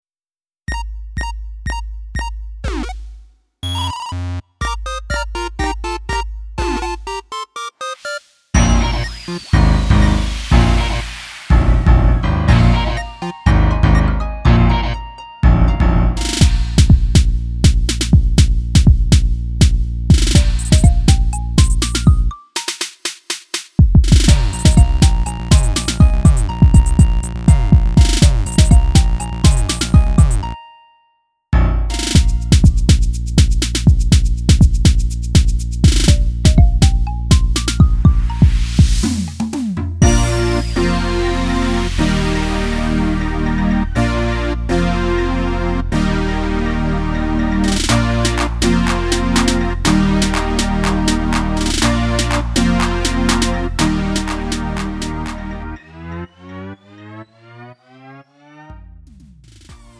가요